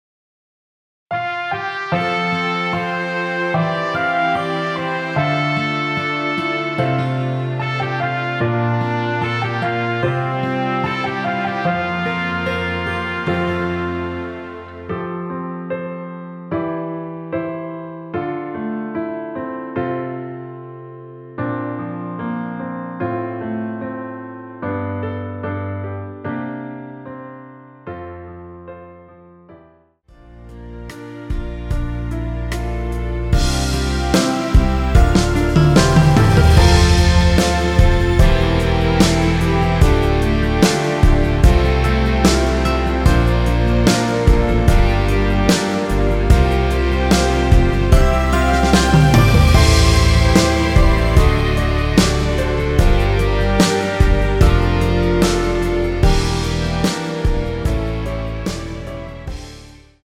여성분이 부르실 수 있는키의 MR입니다.(미리듣기 확인)
원키에서(+5)올린 MR입니다.
앞부분30초, 뒷부분30초씩 편집해서 올려 드리고 있습니다.